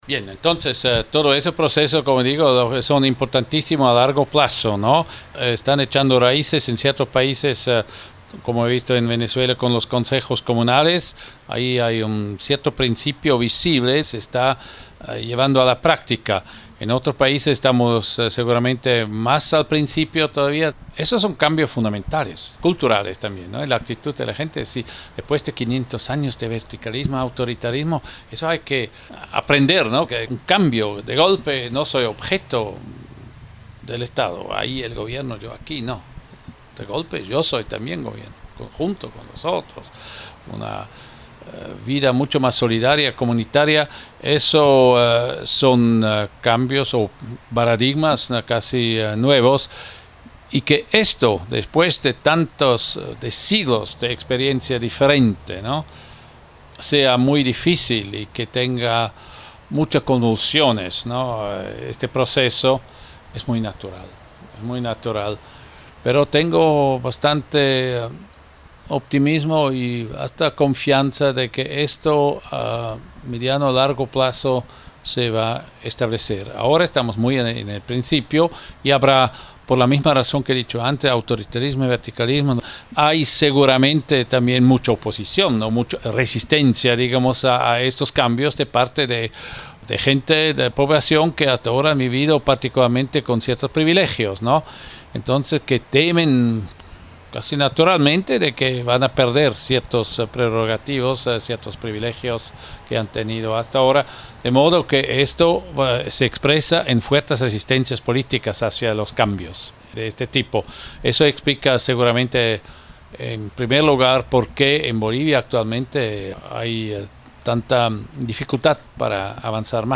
Walter Suter, ex embajador suizo, en entrevsita con swissinfo.